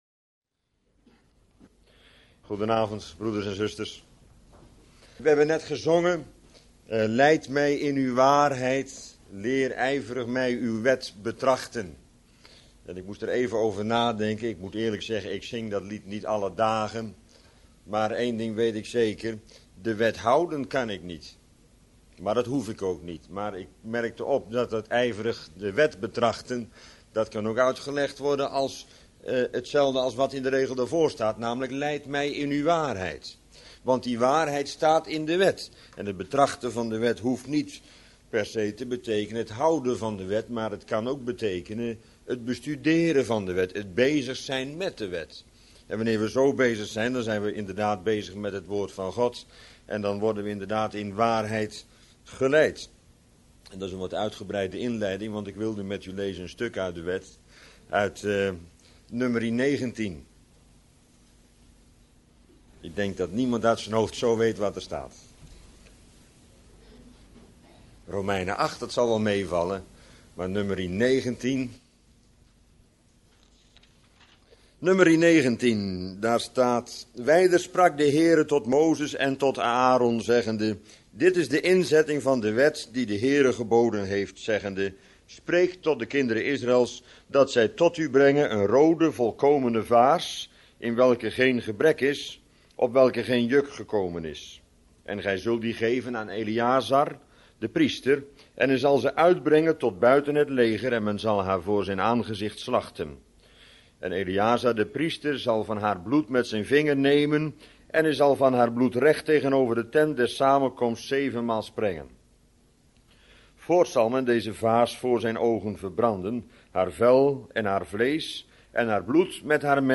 Bijbelstudie lezing onderwerp: De Rode Vaars (Num.19)